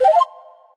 Rosa_reload_01.ogg